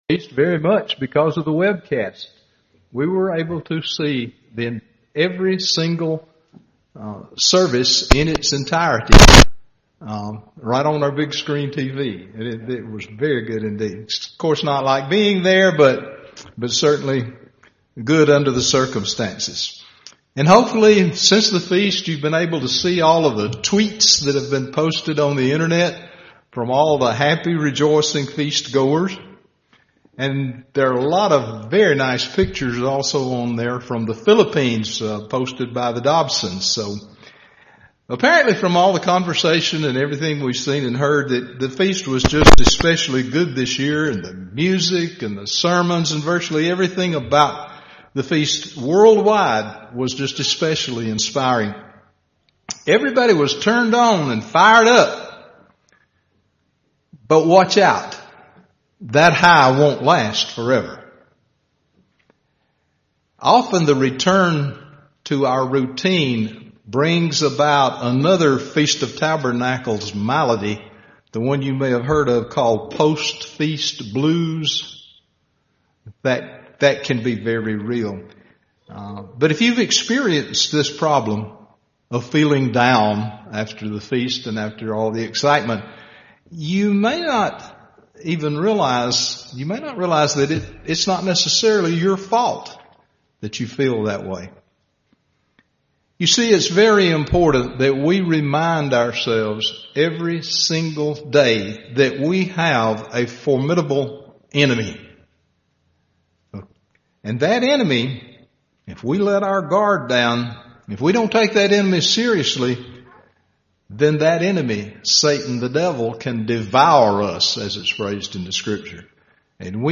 This sermon covers a twofold plan for combating such spiritual problems.